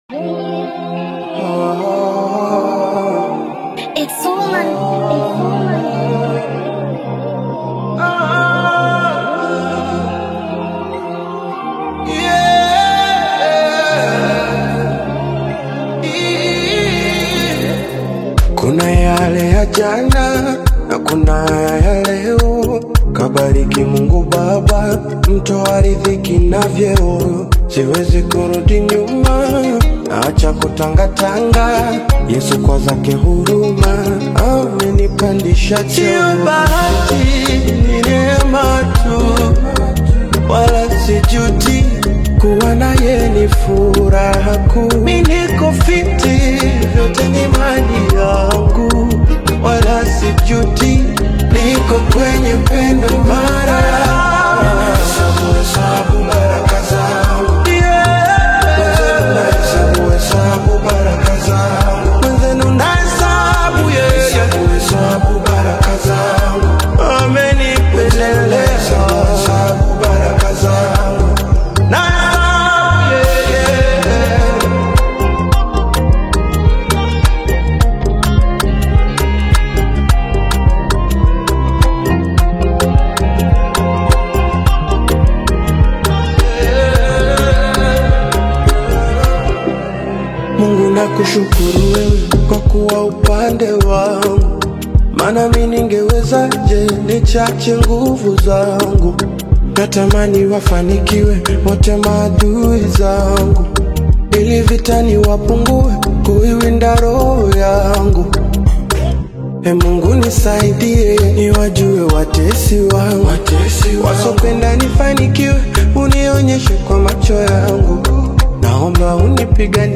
rich worship melodies
Genre: Gospel